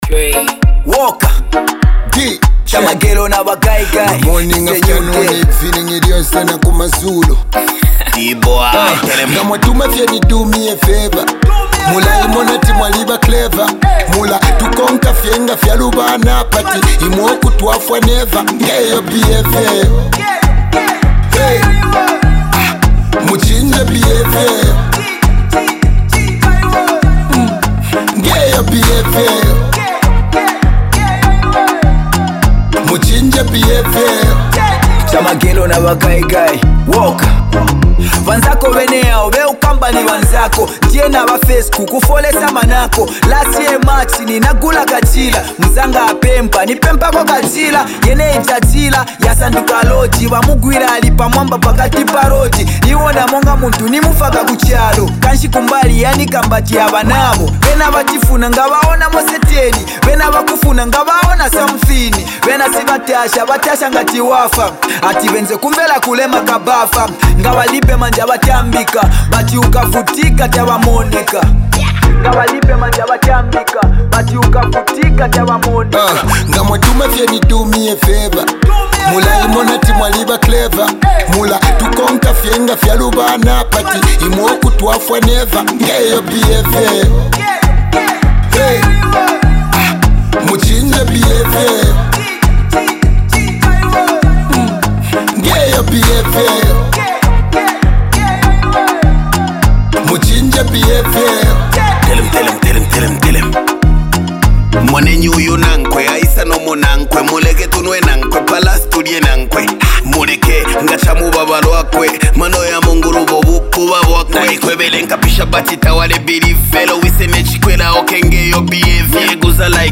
smooth vocals
The production is sleek and upbeat